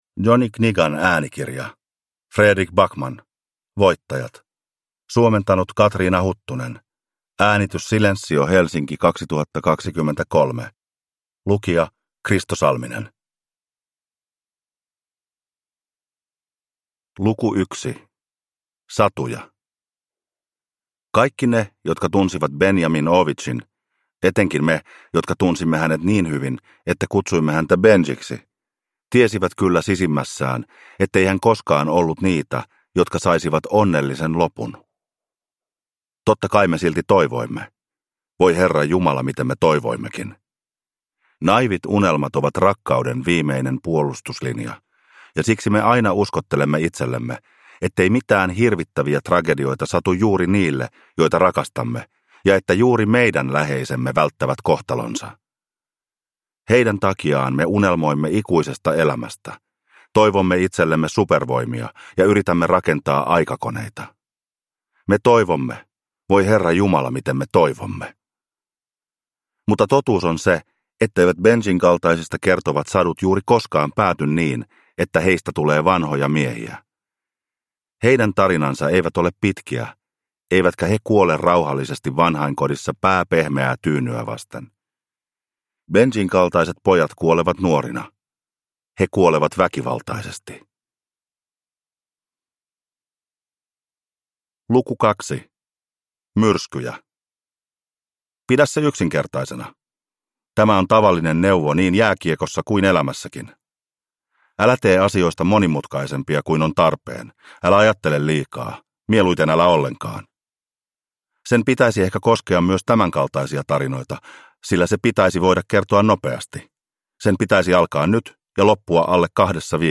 Voittajat – Ljudbok